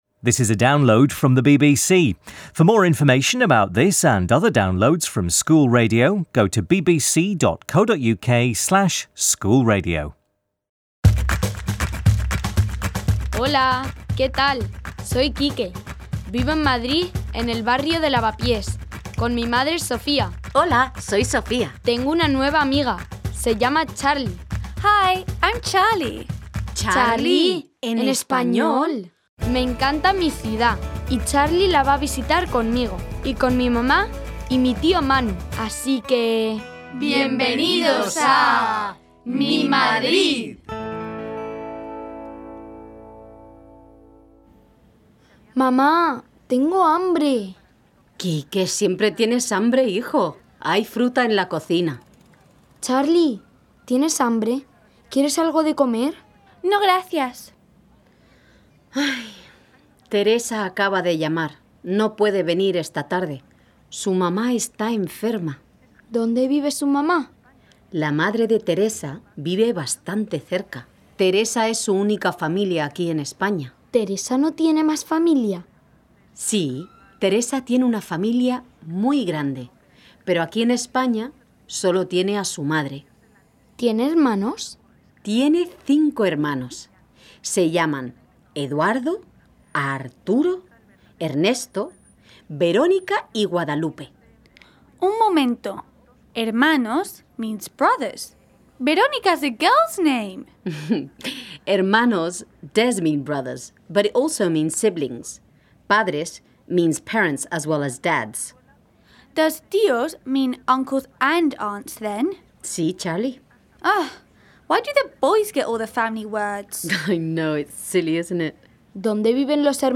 Charlie, Quique and Sofía talk about their own families and about the people in Teresa's family who live all over the world. Teresa then tells a story about an alien, Cho, who comes to Earth and Uncle Manu sings about his own close-knit family. Key vocabulary includes family members and expressions using the verb 'tener'. Pronunciation includes focus on the 's'/'z' sound.